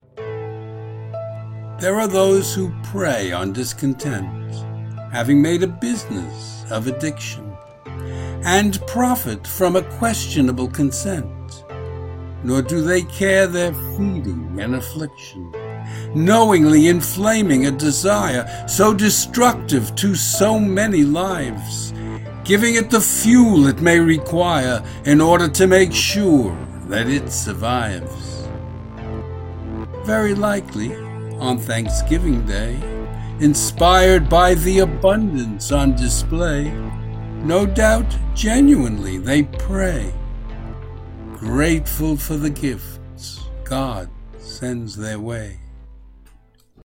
Video and Audio Music: